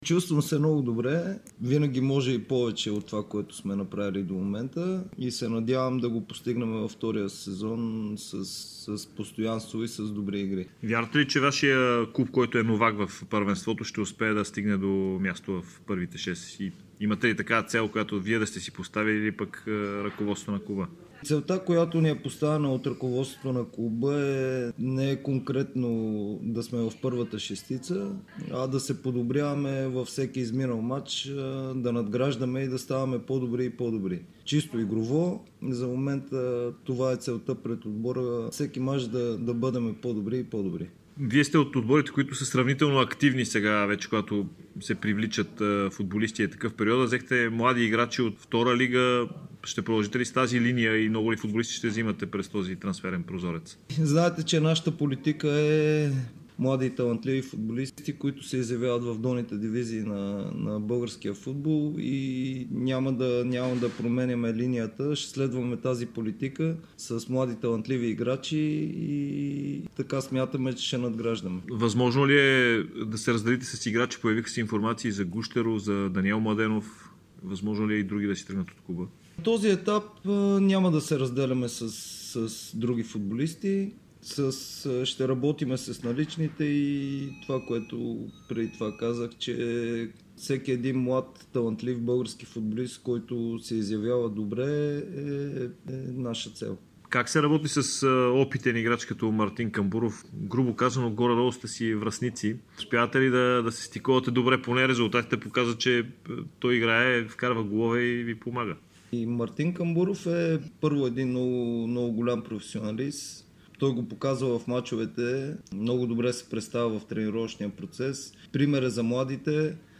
специално интервю пред Дарик радио и dsport. Той говори за целите пред новака в българския елит.